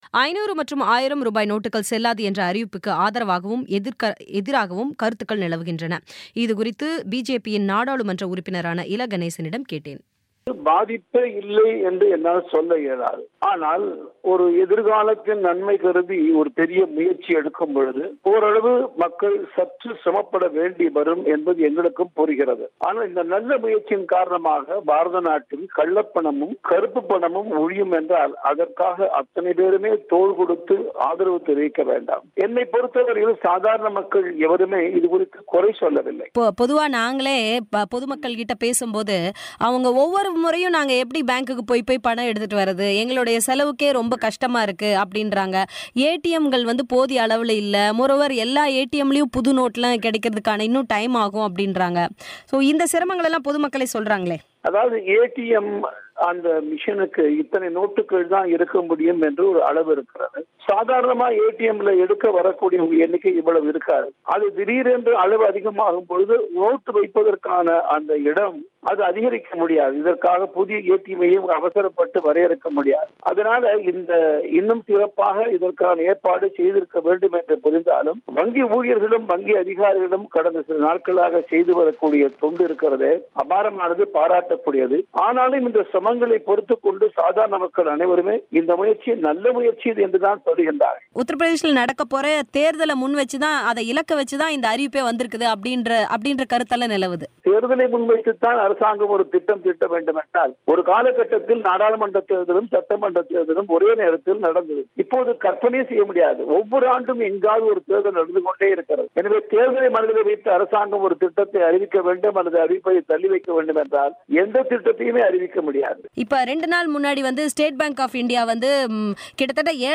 கறுப்பு பண ஒழிப்பு நடவடிக்கை- ‘’தேச பக்தி உள்ளவர்கள் ஏற்பார்கள்``-இல.கணேசனின் பேட்டி